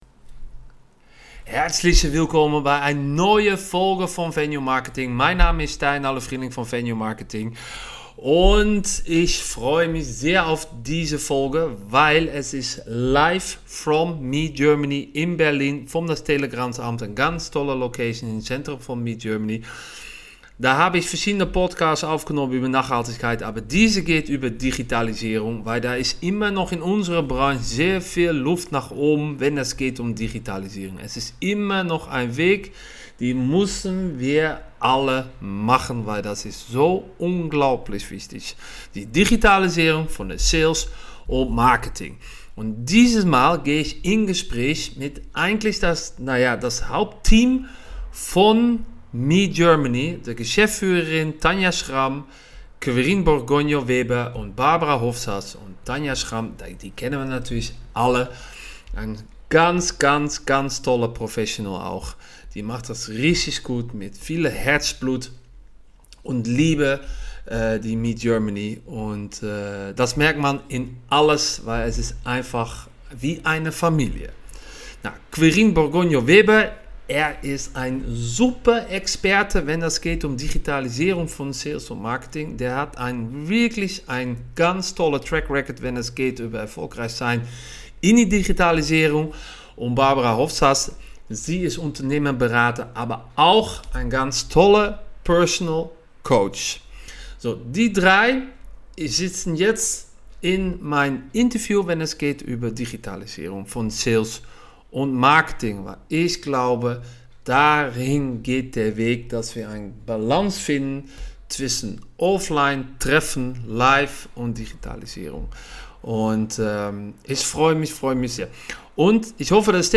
26. Digitalisierung in MICE: Insights von Meet Germany I Live from Meet Germany Berlin ~ B2B Marketingpodcast für Eventlocations & Hotels Podcast